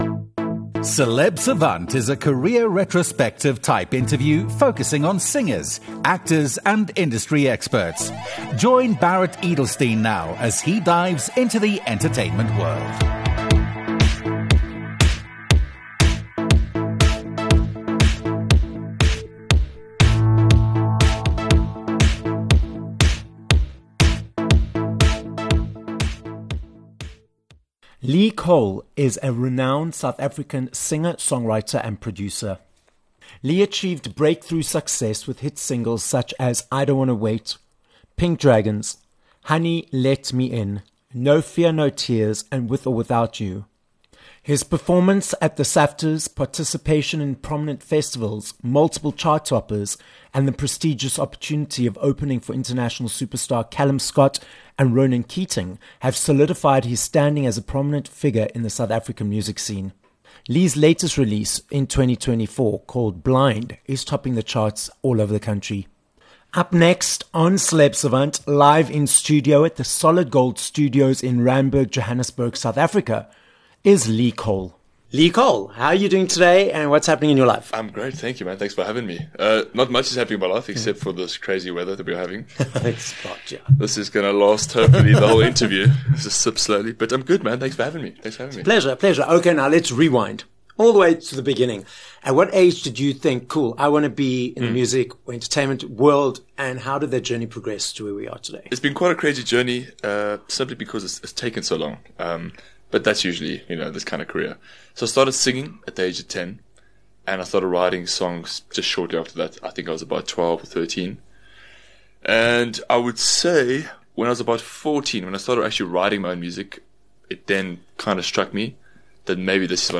Interview
This episode of Celeb Savant was recorded live in studio at Solid Gold Podcasts in Randburg, Johannesburg, South Africa.